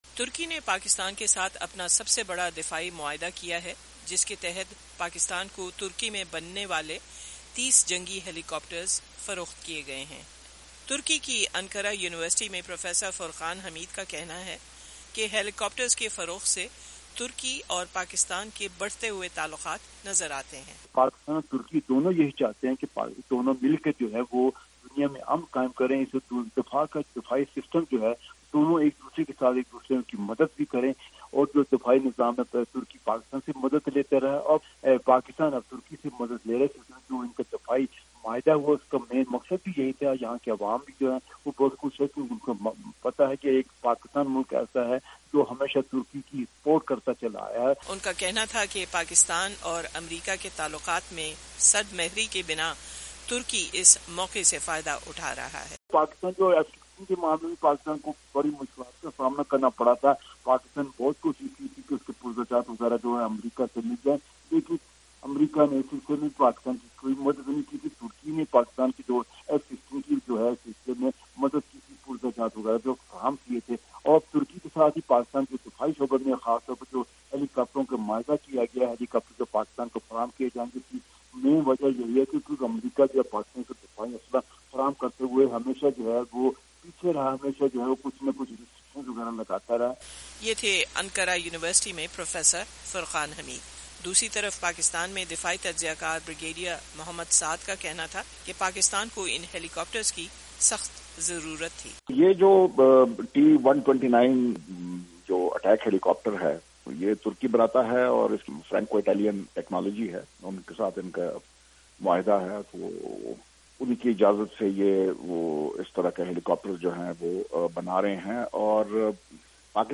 وائس آف امریکہ کی اردو سروس کے پروگرام جہاں رنگ میں پاکستان اور ترکی کے درمیان بڑھتے ہوئے دفاعی تعاون اور حربی سامان کی خریداریوں پر گفتگو کی گئی۔